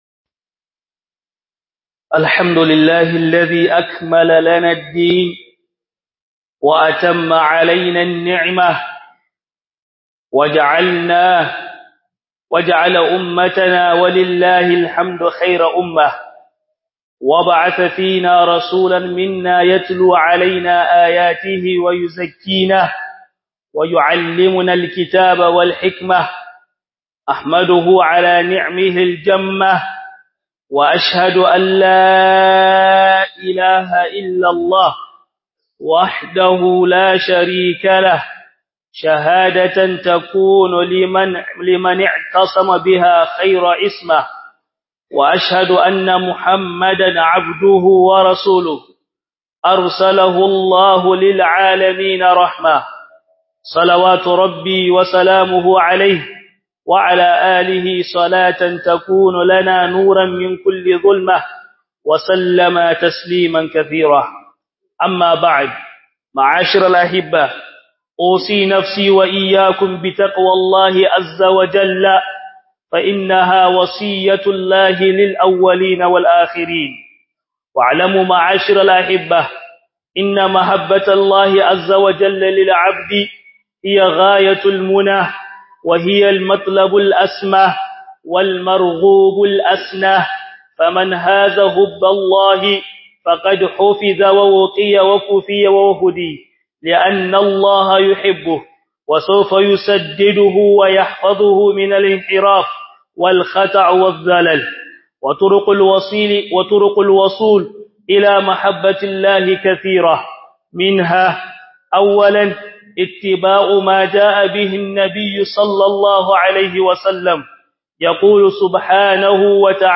Khudubar Juma'ah 30 Jumada Thaani 1445AH MIssa - HUDUBA